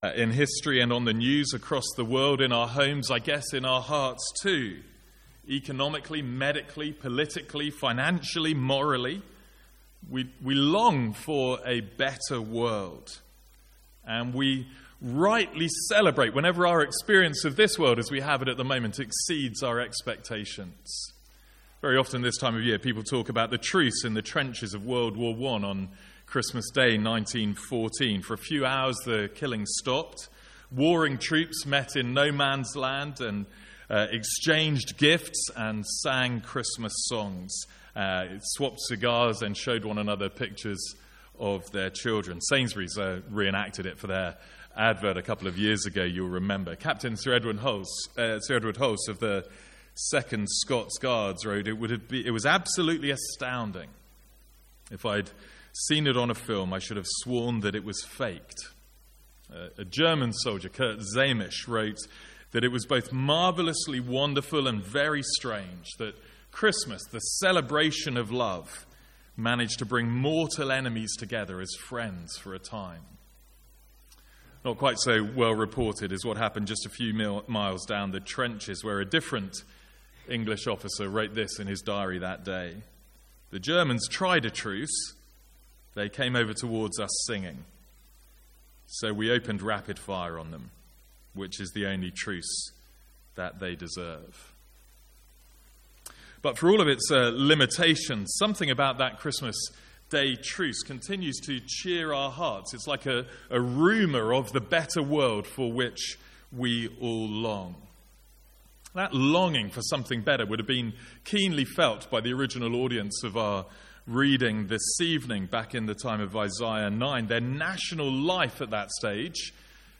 From our evening carol service on 11th December.
(First part of recording is missing)